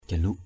jaluk.mp3